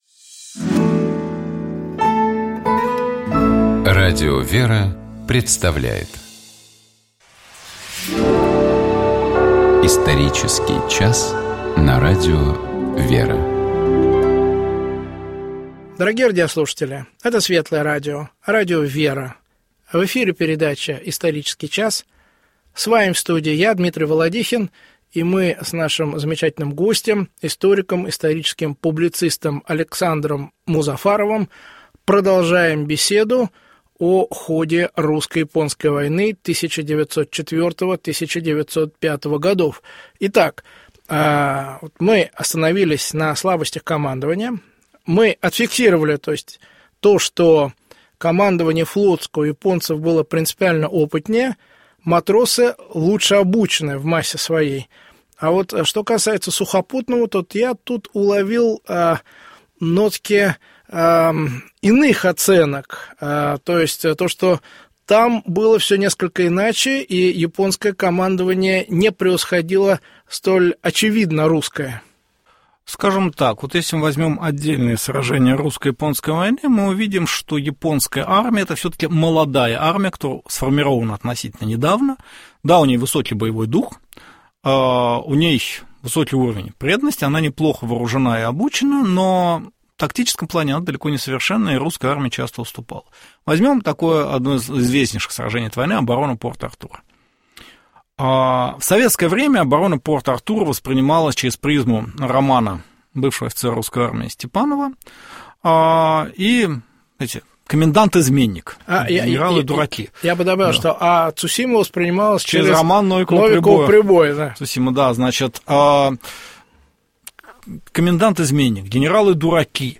В эфире передача «Исторический час».